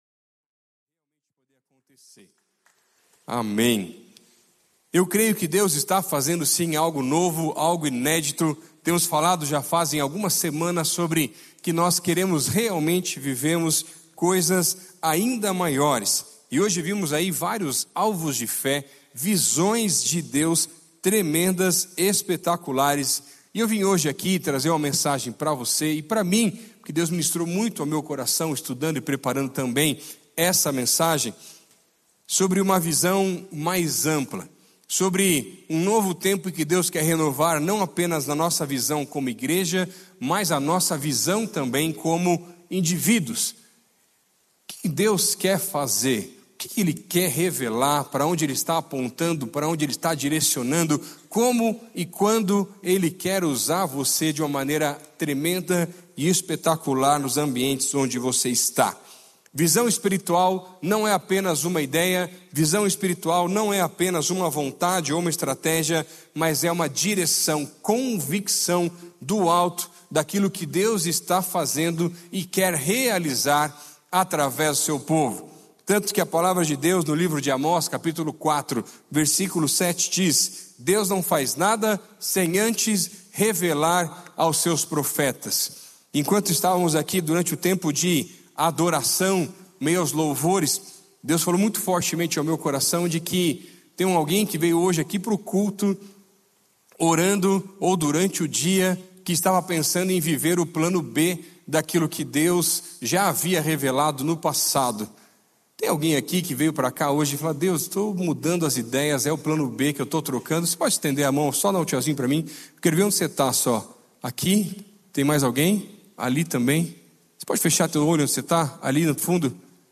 Culto ONE